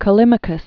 (kə-lĭmə-kəs) Third century BC.